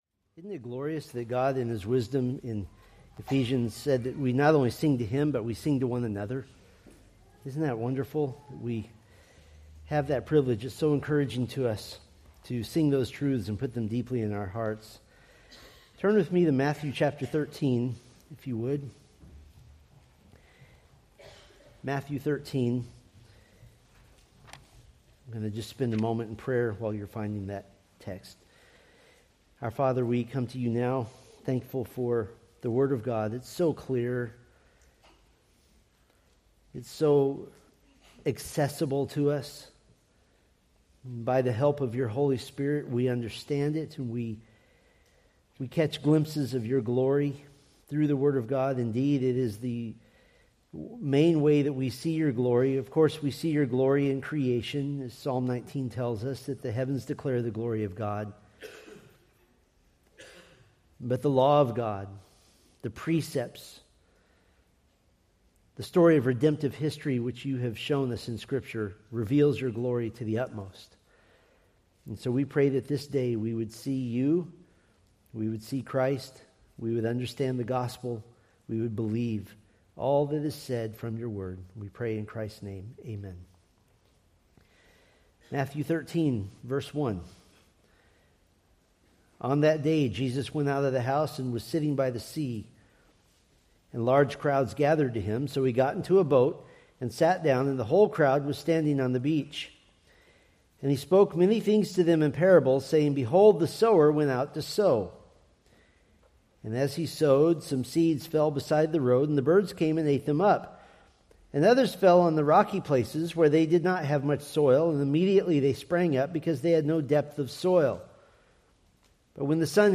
Preached February 8, 2026 from Matthew 13:18-23